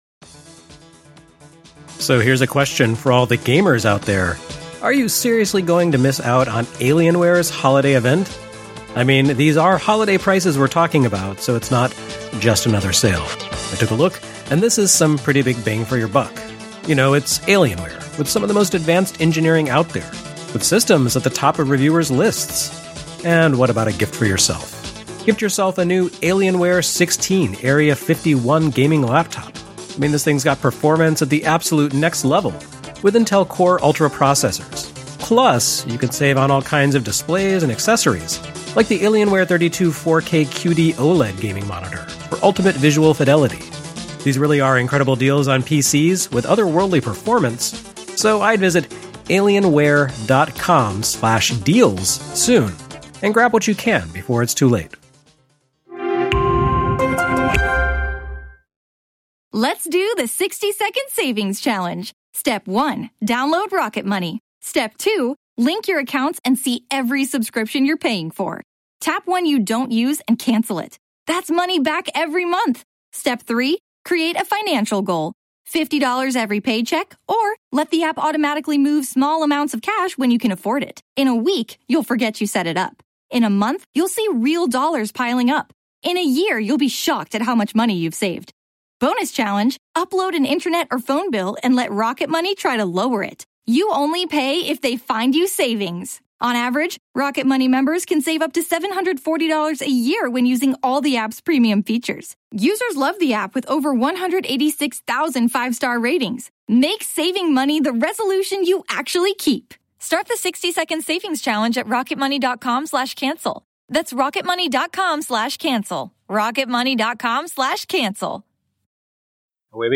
Live from MAGfest 2019!